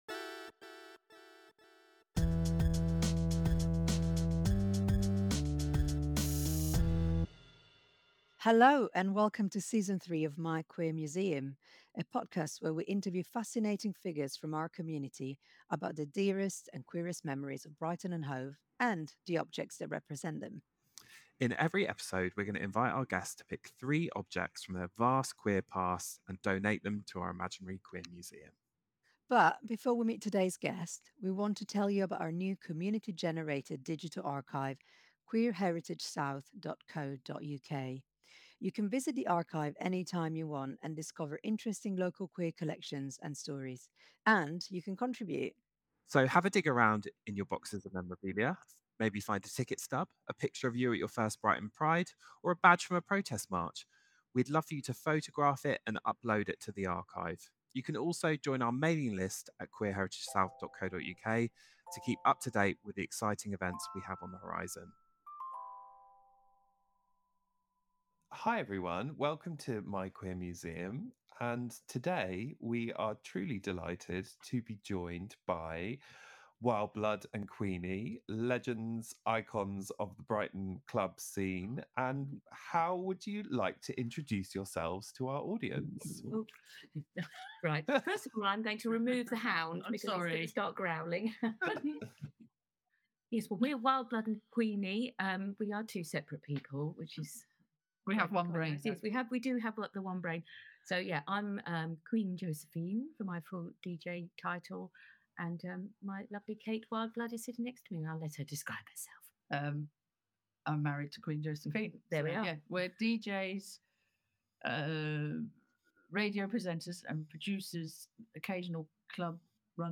Recorded remotely on March 6, 2023